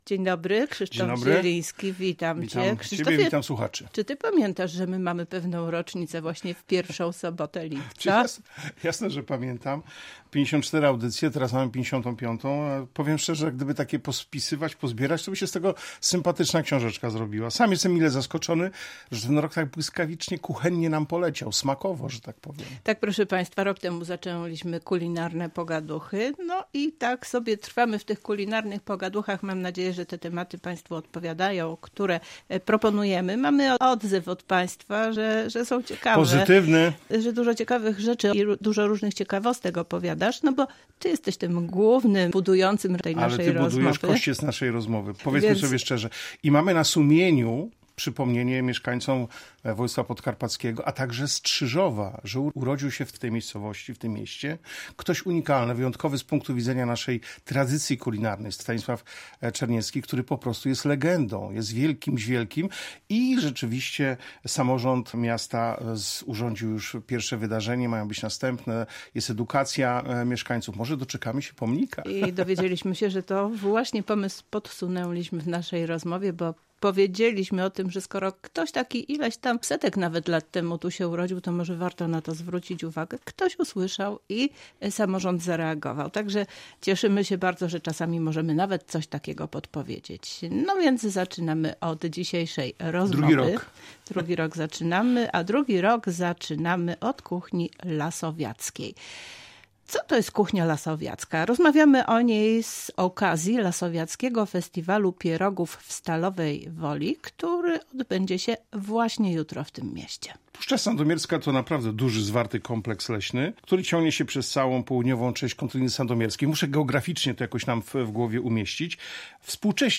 W „Kulinarnych pogaduchach” rozmowa o lasowiackiej kuchni, przy okazji Festiwalu pierogów lasowiackich, który odbędzie się w Rozwadowie 6 lipca. https